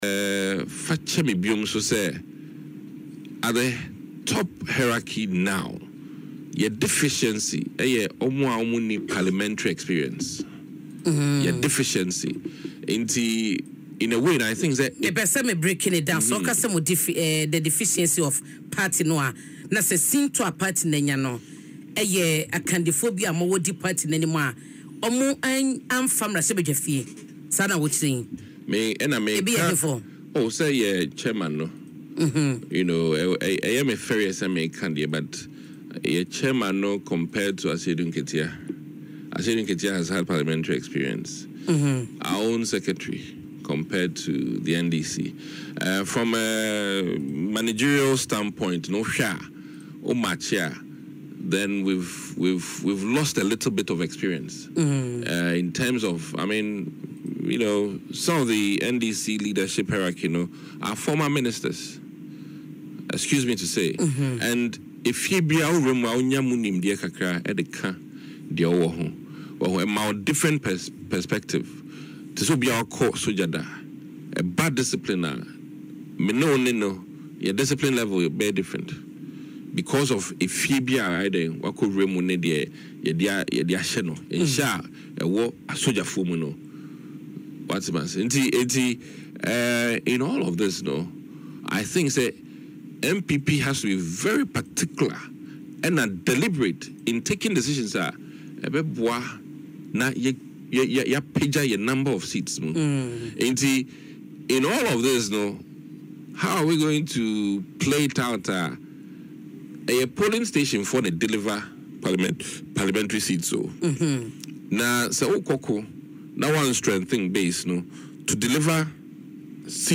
Dr. Kissi made these comments in an interview on Adom FM’s Dwaso Nsem.